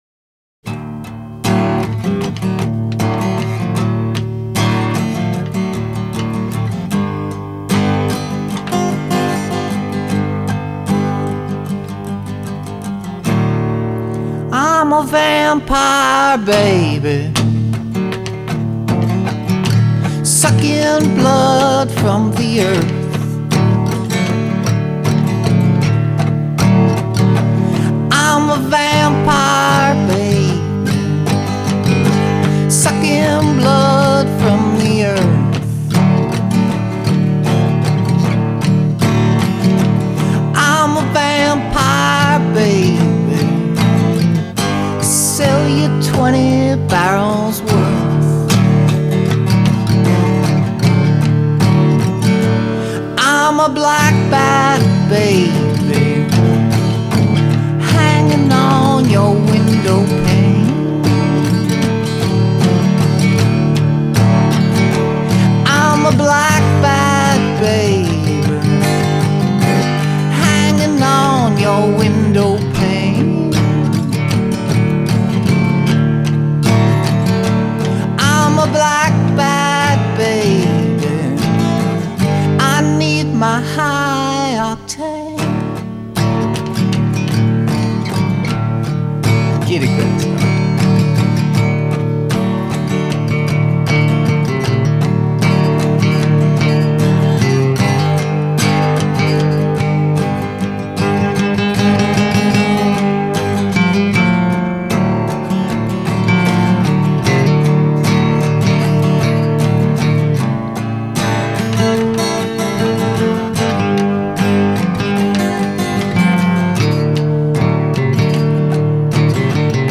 faithful reworking